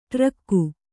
♪ ṭrakku